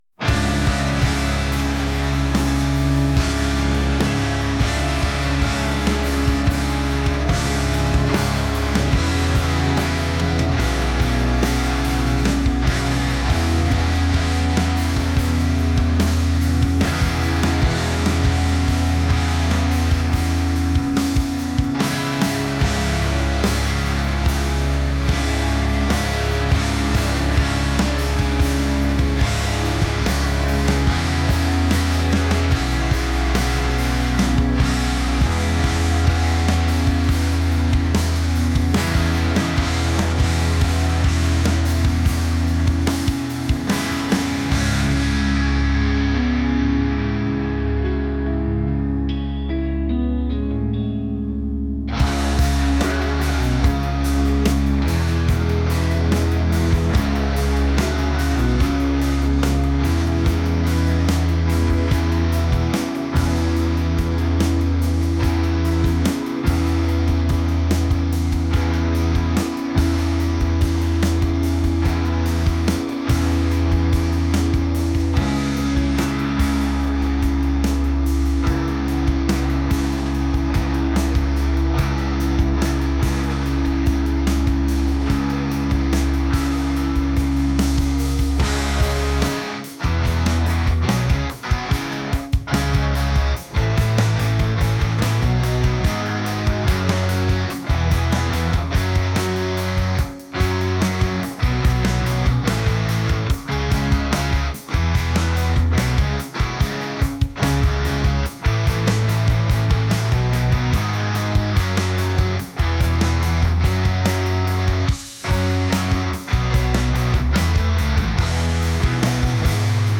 rock | intense